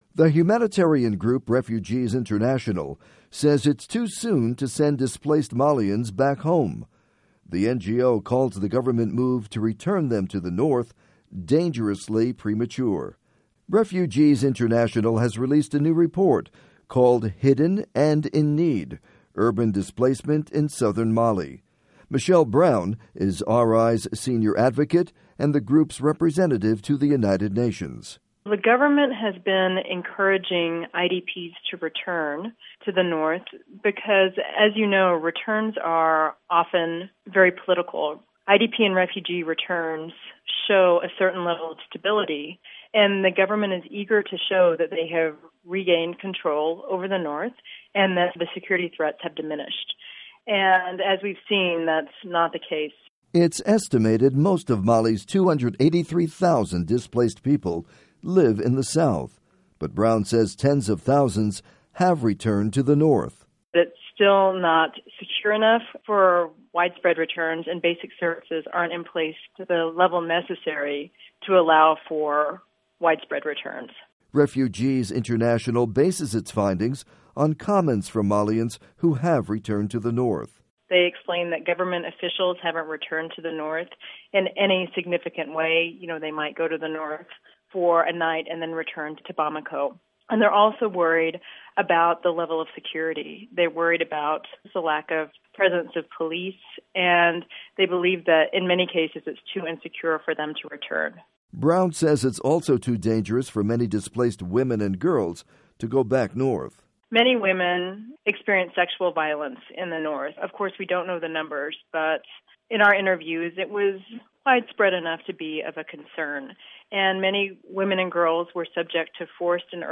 report on Mali IDPs